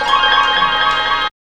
0504R PIANFX.wav